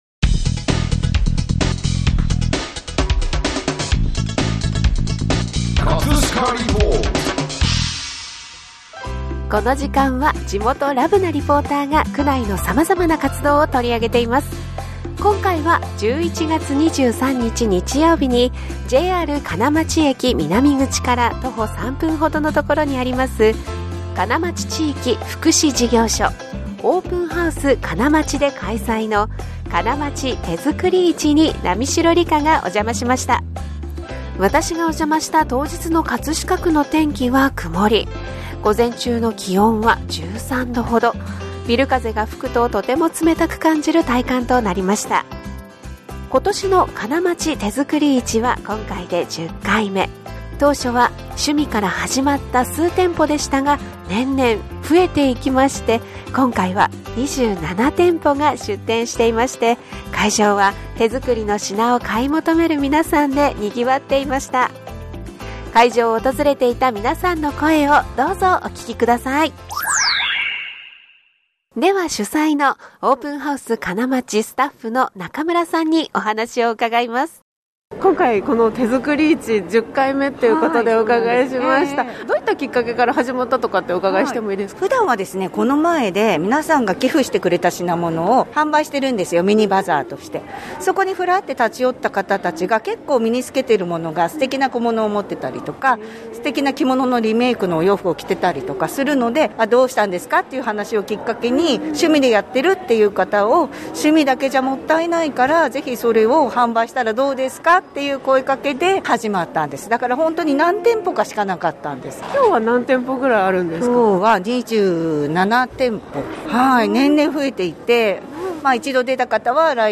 金町手づくり市は今回で10回目、最初は趣味から始まった数店舗が年々増えて、今年はは27店が出店していました。 会場は、手づくりの品を買い求める皆さんでにぎわっていました。 会場を訪れていた皆さんの声をどうぞお聴きください。